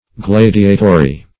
Gladiatory \Glad"i*a*to*ry\, a.